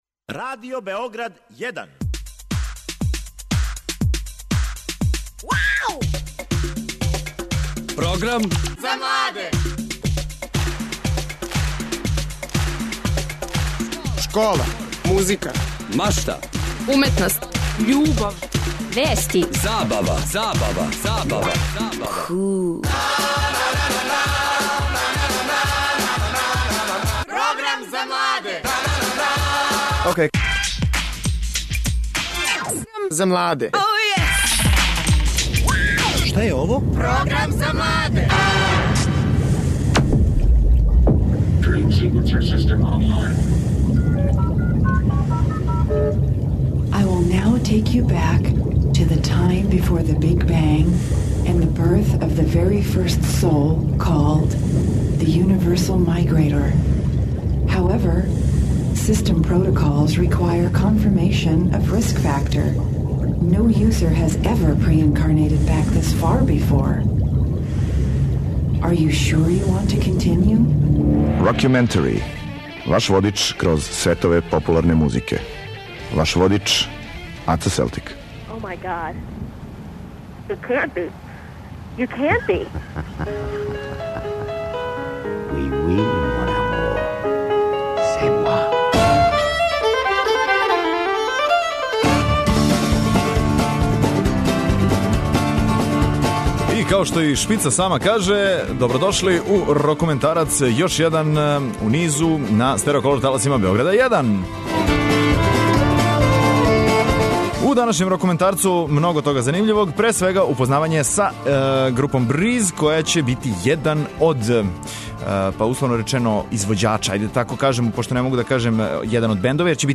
Представљамо и преслушавамо,такође,и нове албуме.